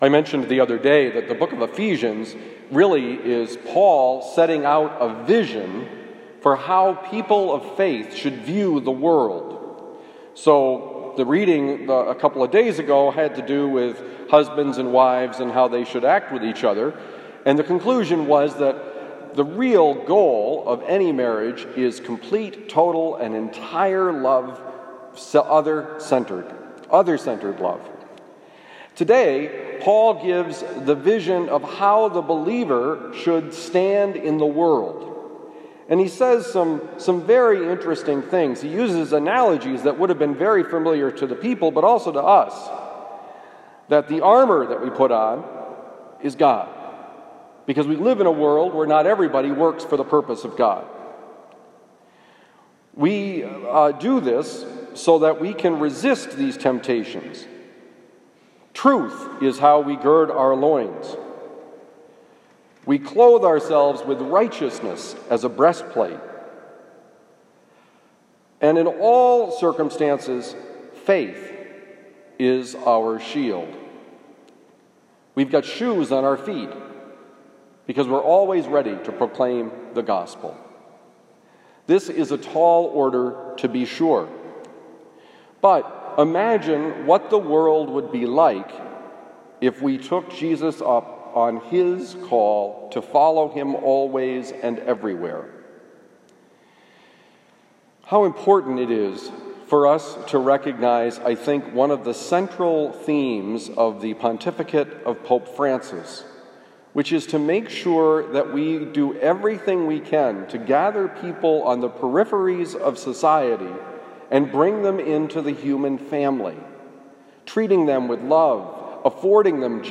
Homily for October 29, 2020
Given at Christian Brothers College High School, Town and Country, Missouri